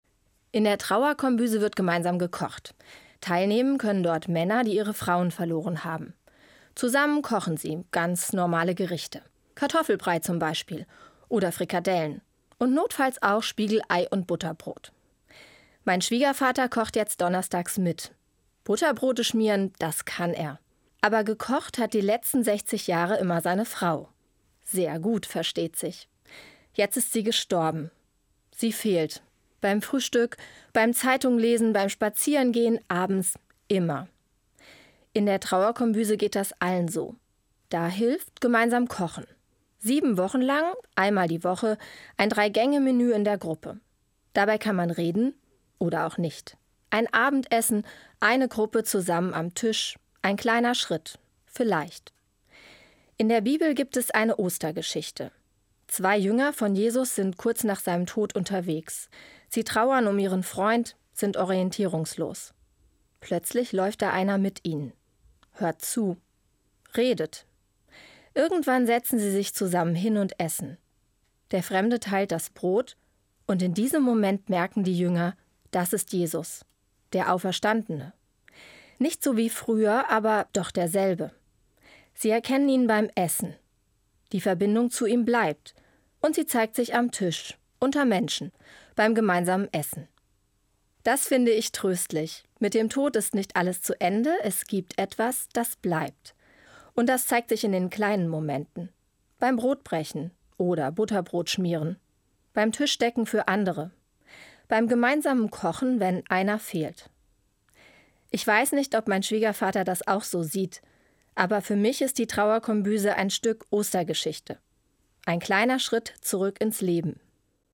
Evangelische Pfarrerin, Herborn